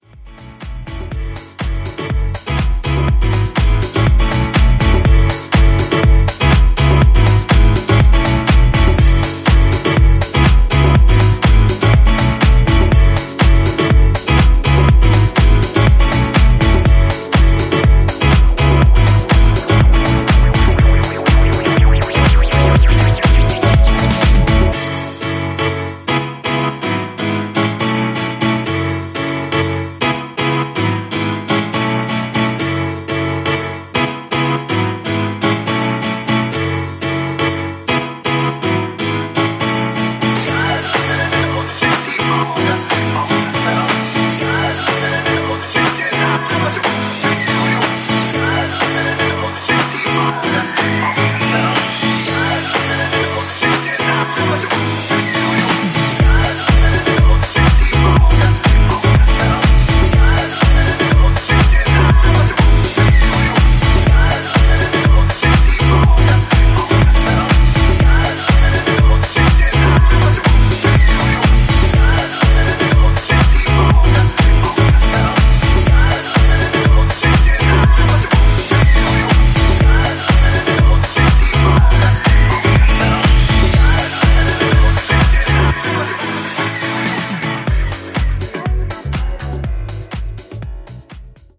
ジャンル(スタイル) DISCO HOUSE / EDITS